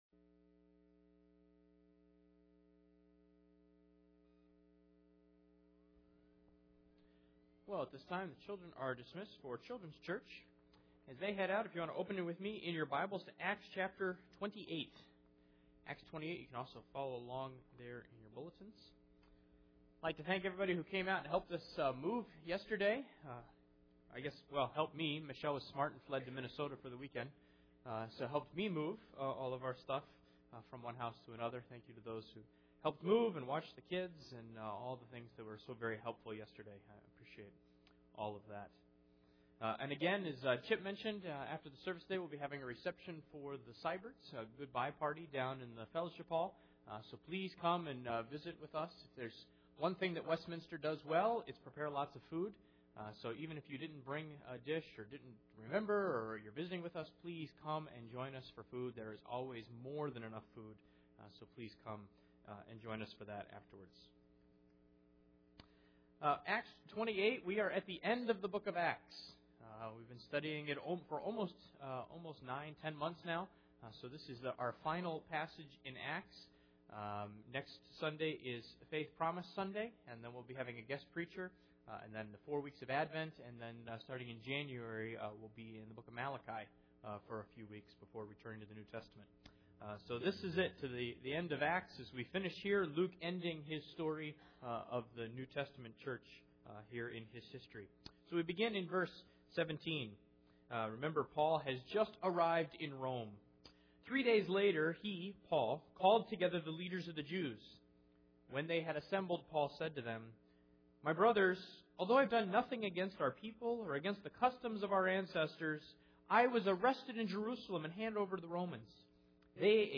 Acts 28:17-31 Service Type: Sunday Morning The end of the book of Acts